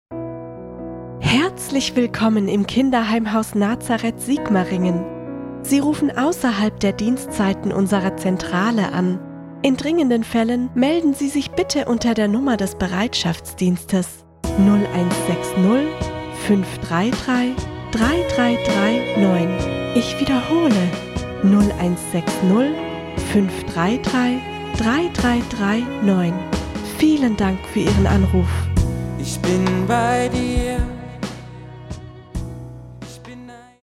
Professionelle Sprecherin
Telefonansage
Telefonansage-Haus-Nazareth-Sigmaringen-neu.mp3